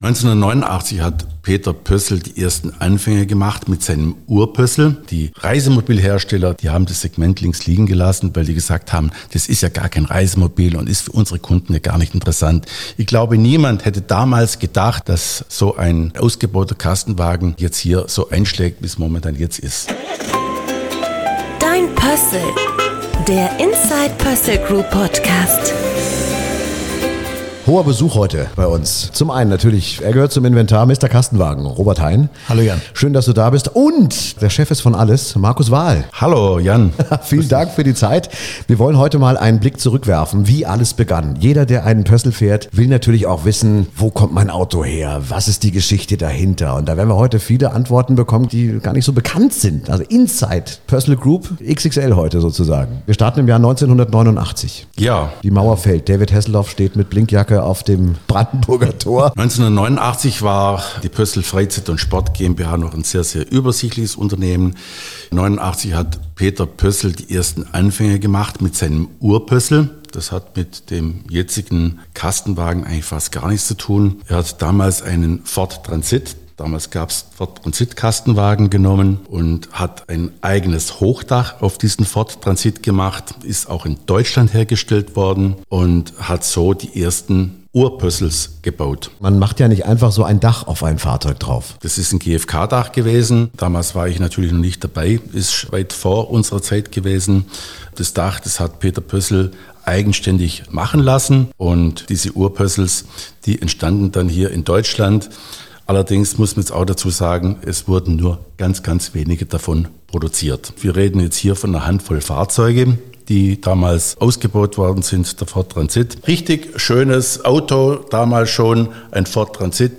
Wir melden uns direkt aus der Halle 3 von der Caravan, Motor und Touristik Messe in Stuttgart.